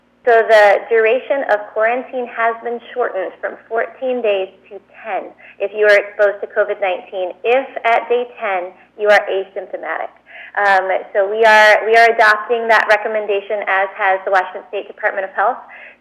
PORT ANGELES – Friday morning’s Covid briefing with Health Officer Dr. Allison Berry Unthank began with updated numbers.